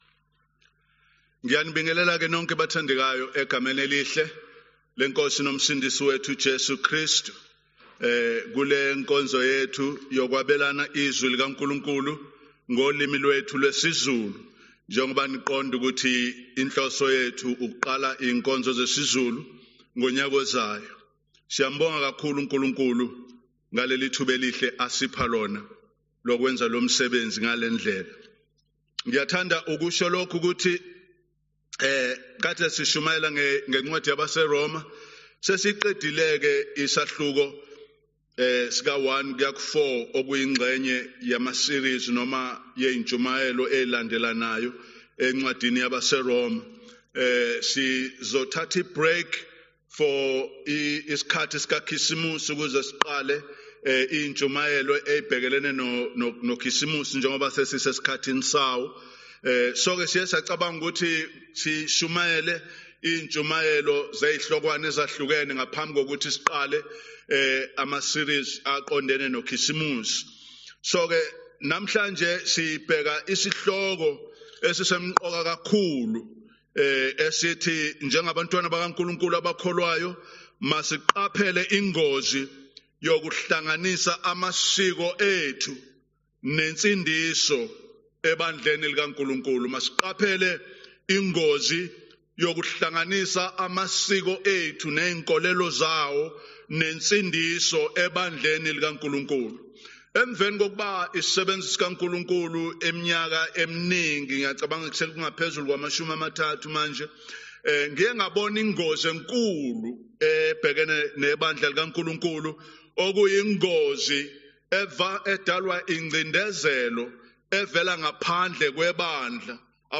Passage: Jeremiah 9:23-26, John 17:1-5 Event: Zulu Sermon